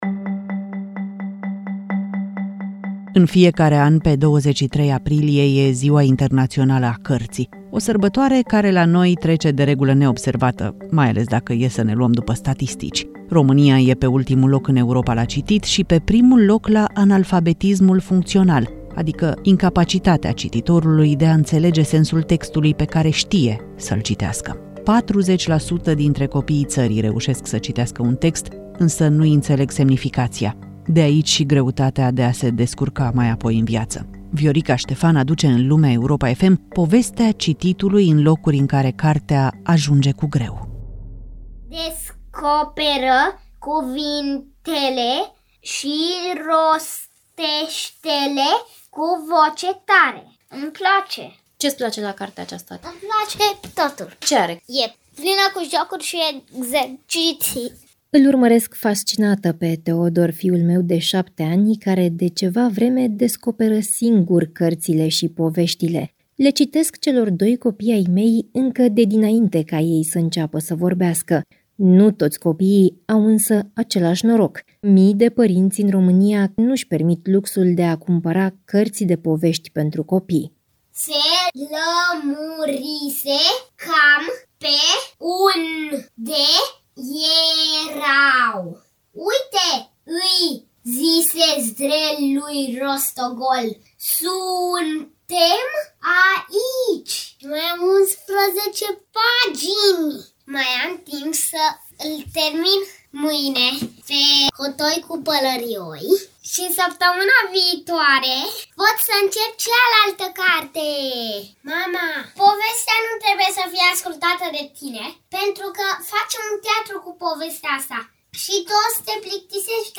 Lumea Europa FM: Cărți pentru copiii de la țară | REPORTAJ